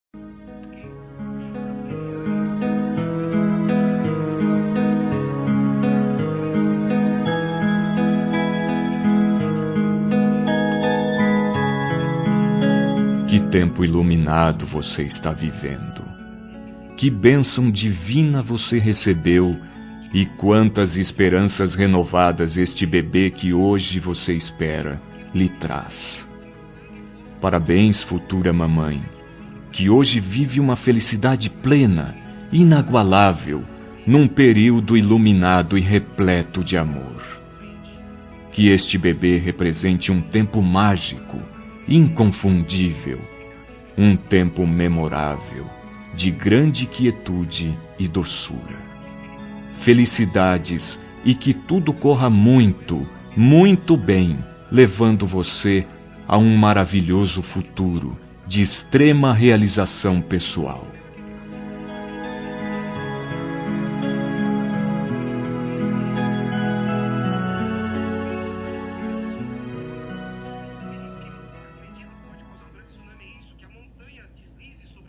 Telemensagem de Gestante – Voz Masculina – Cód: 6635